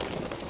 Gear Rustle Redone
tac_gear_36.ogg